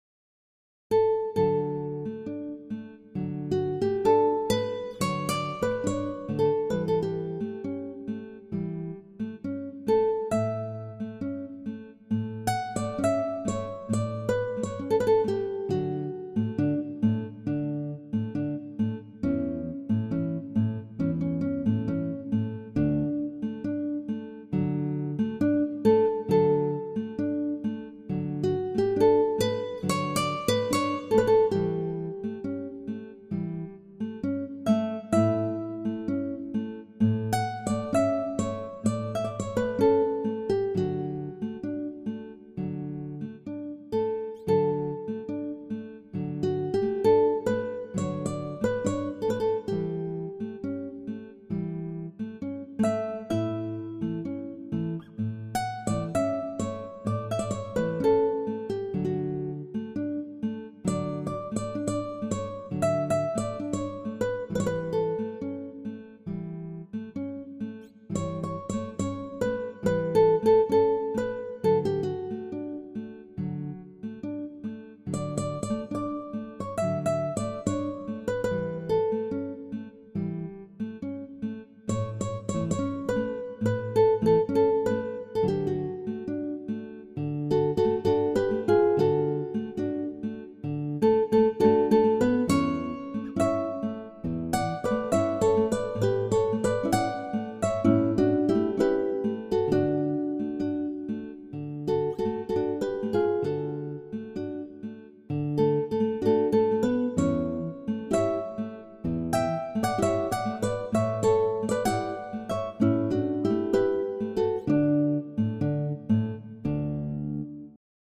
こちらはギターのファイルですが、アコーディオンの入ったファイルもあります。